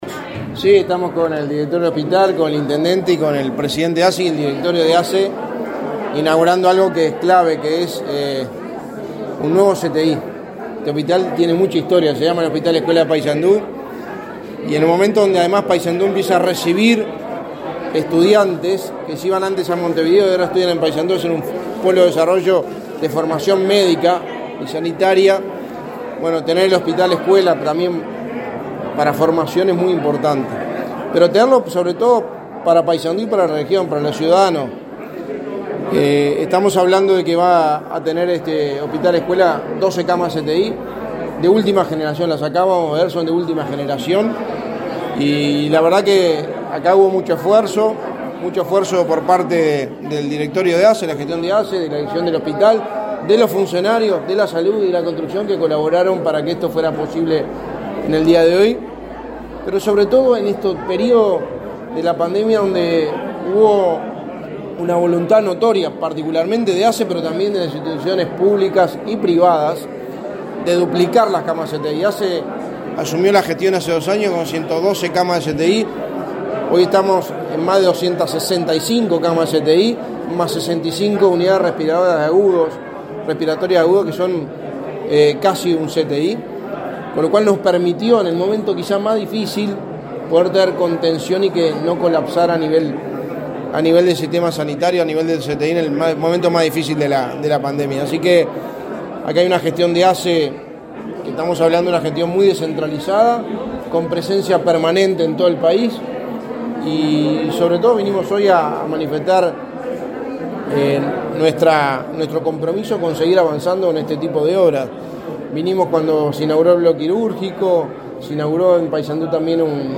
Declaraciones a la prensa del secretario de Presidencia y del presidente de ASSE
Declaraciones a la prensa del secretario de Presidencia y del presidente de ASSE 18/03/2022 Compartir Facebook X Copiar enlace WhatsApp LinkedIn Este viernes 18, el secretario de Presidencia, Álvaro Delgado, y el presidente de ASSE, Leonardo Cipriani, dialogaron con la prensa, luego de participar de la inauguración de un CTI del hospital de Paysandú.